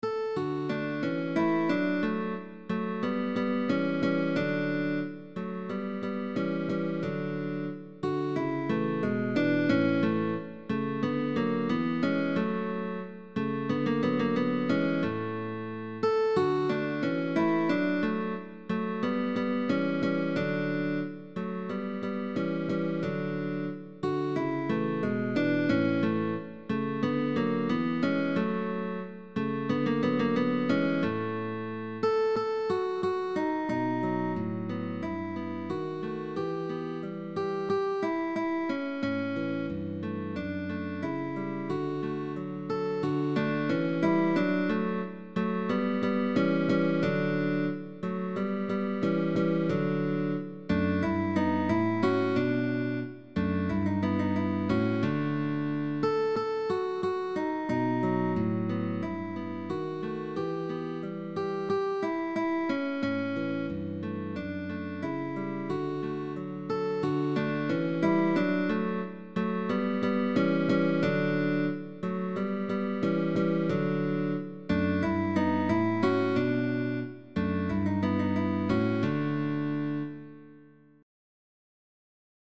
Change of Dinamics.
Classicism